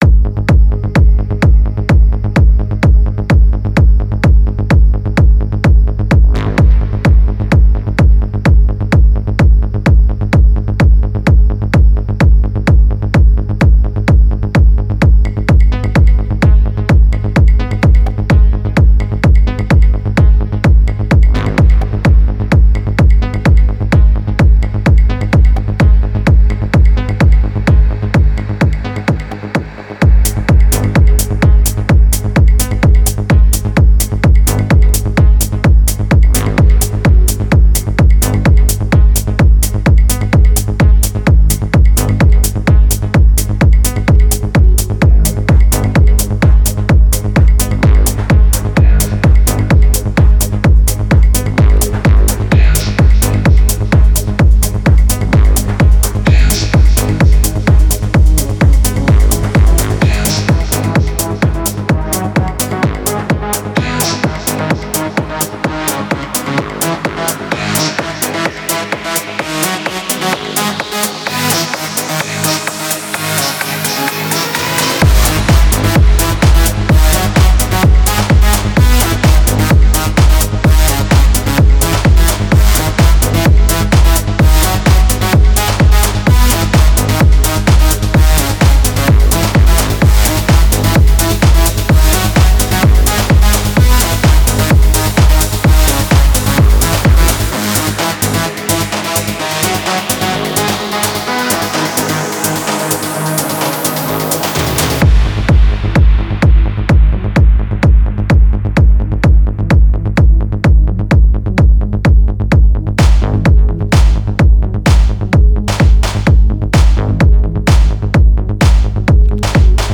• Жанр: Dance, Electronic, Techno